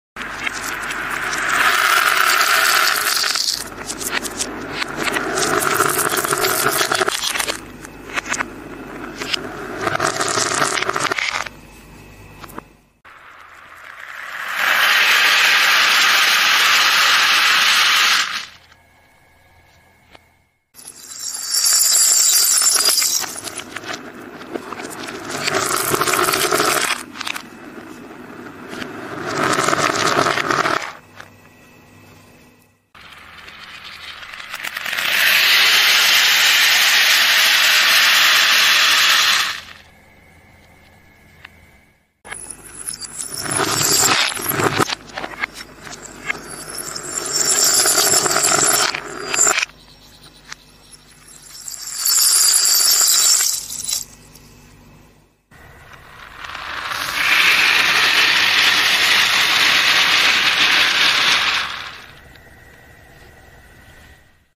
ASMR Reverse video. Beads, bells